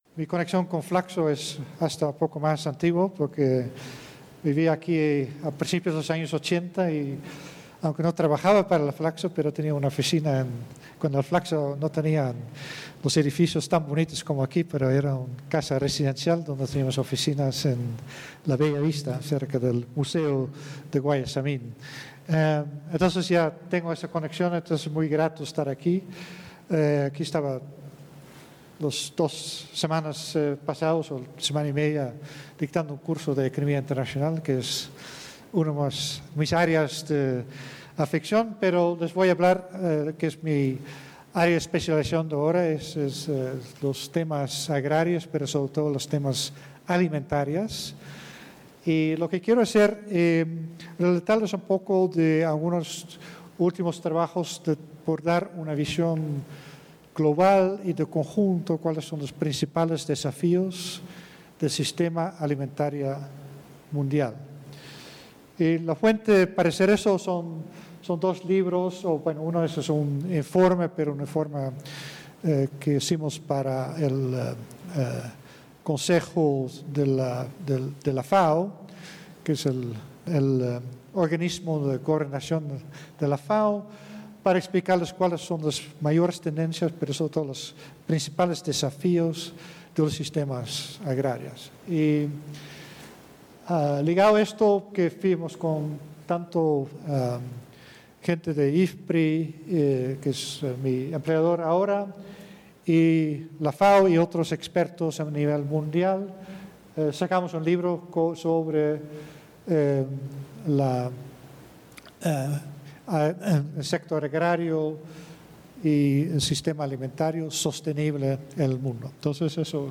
Coloquio Desafíos del sistema alimentario a nivel mundial